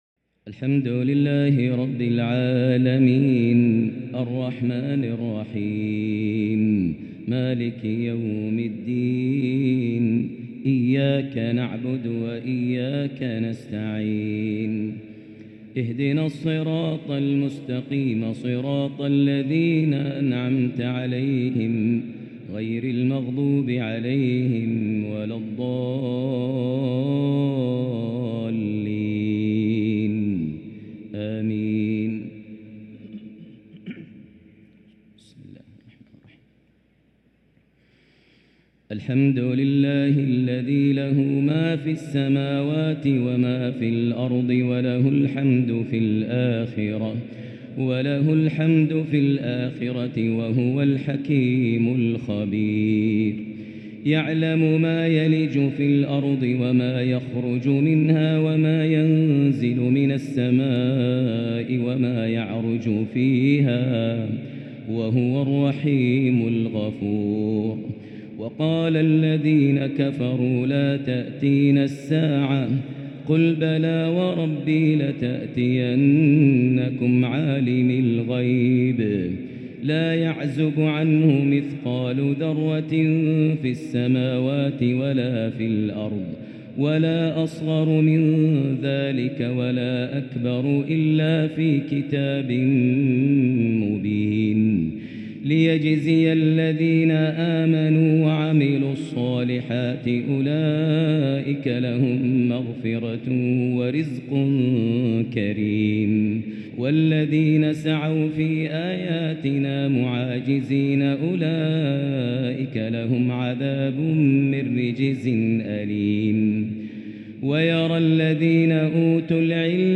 تهجد ليلة 24 رمضان 1444هـ سورة سبأ | Tahajjud 24 st night Ramadan 1444H Surah Saba > تراويح الحرم المكي عام 1444 🕋 > التراويح - تلاوات الحرمين